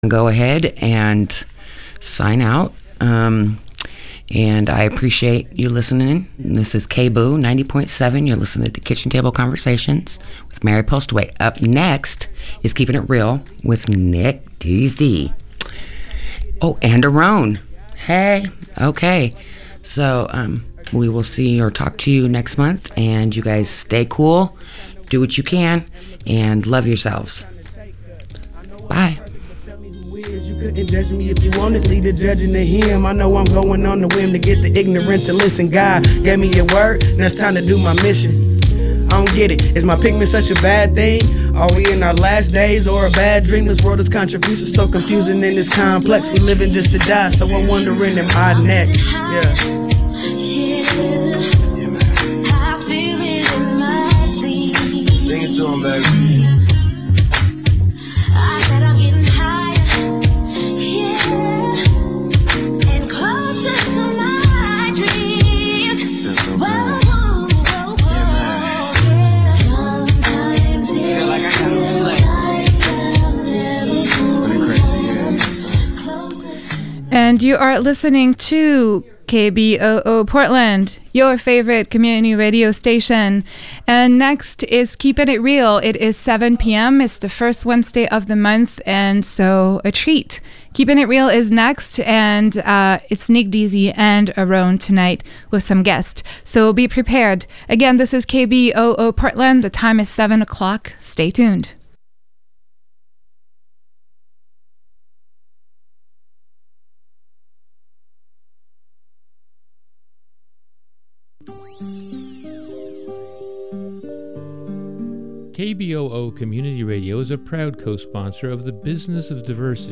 Keepin It Real is a public affairs, talk radio program that strives to educate and to offer a forum for people to speak their truth while being open minded and inclusive.